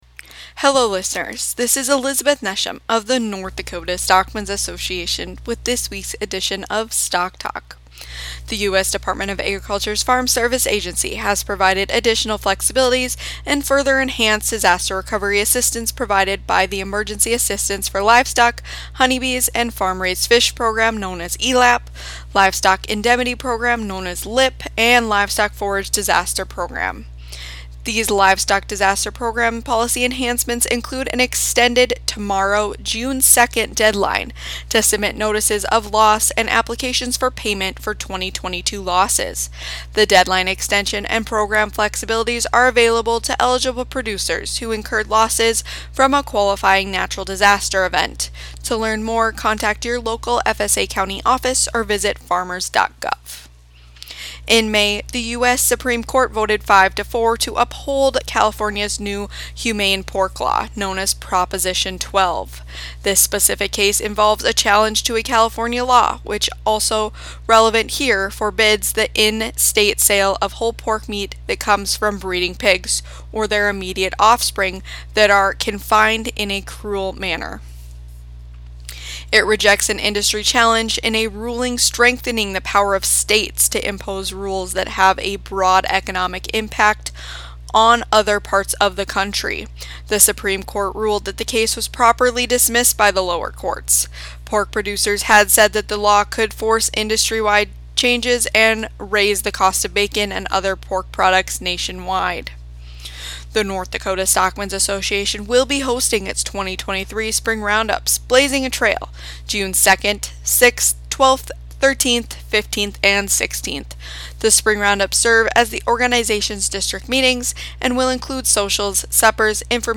Listen to our weekly radio report that airs every Thursday on KDIX radio in Dickinson.